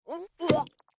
Npc Catspit Sound Effect
npc-catspit.mp3